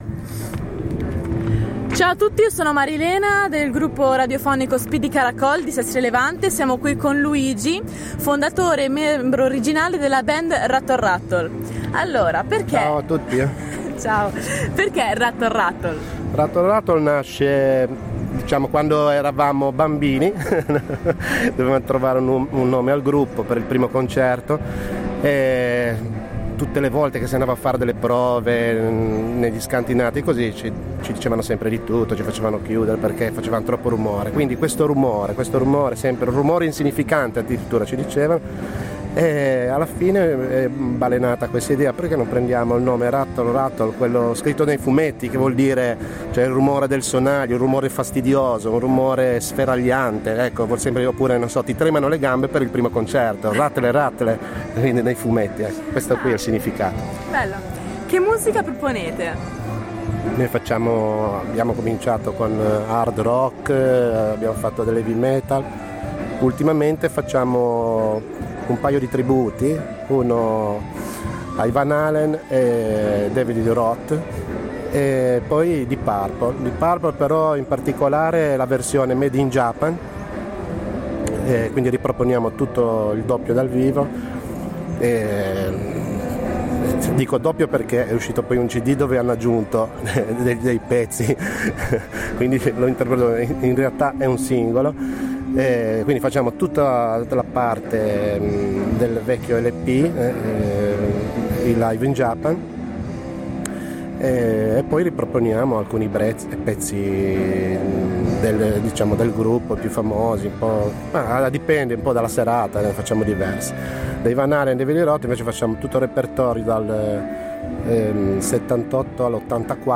Musicista intervista
In occasione della chiusura del Muddy Waters abbiamo intervistato anche i gruppi che hanno suonato quella sera